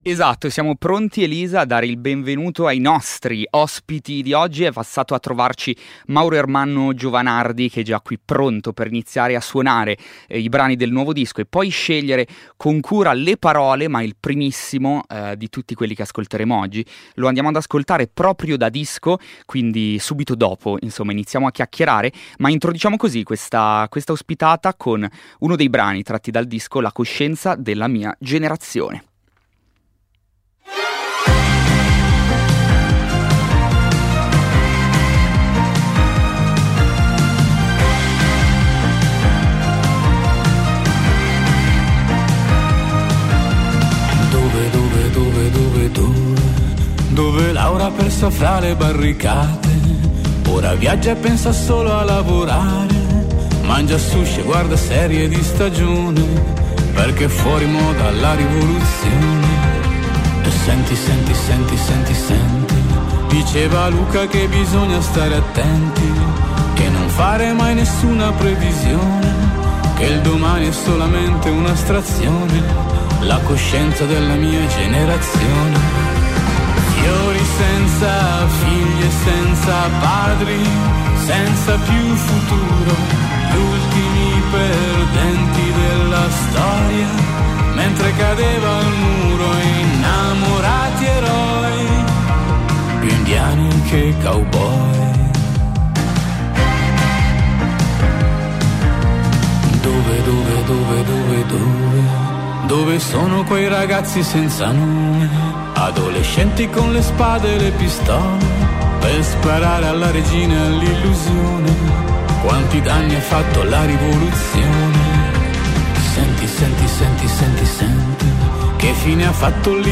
Ascolta l’intervista a Mauro Ermanno Giovanardi.